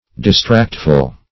Distractful \Dis*tract"ful\, a.
distractful.mp3